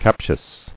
[cap·tious]